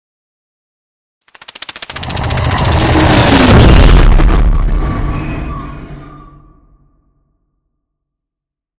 Scifi passby whoosh long
Sound Effects
futuristic
high tech
intense
pass by
vehicle